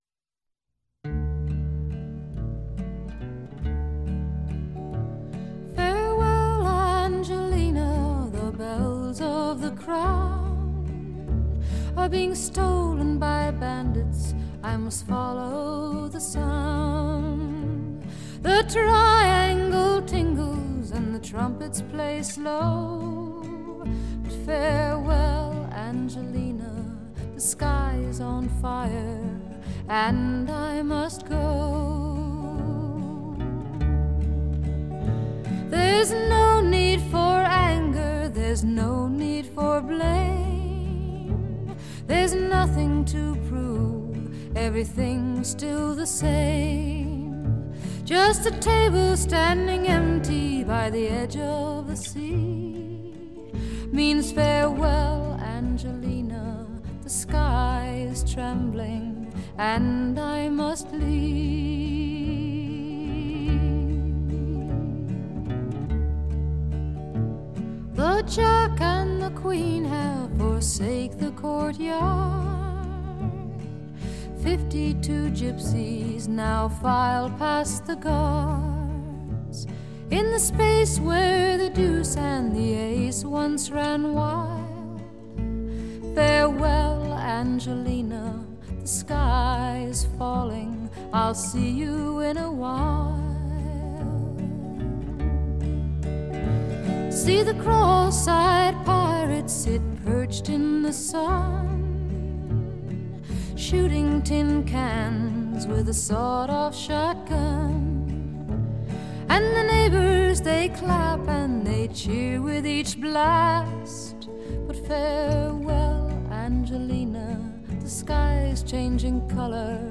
撼動心弦的顫音，悠揚柔軟而充滿強韌勁道的高音演唱